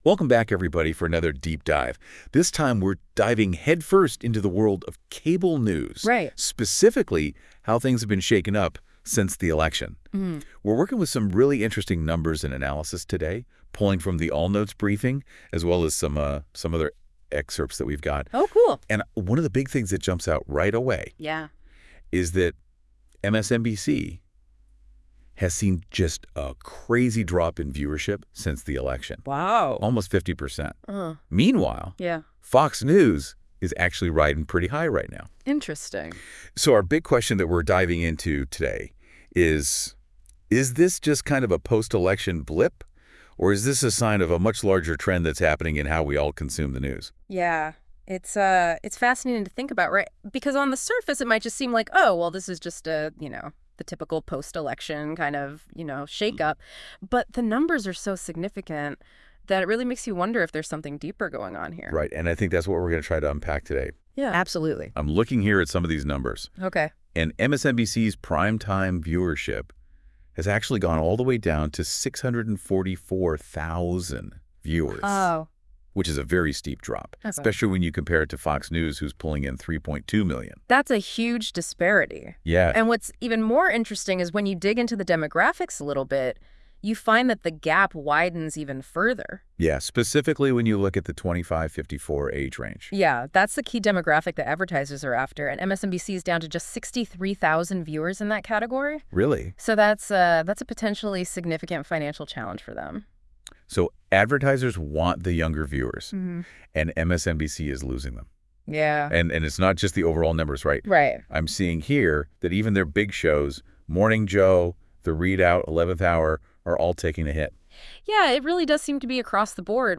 Listen to the convo on MSNBC’s collapse…..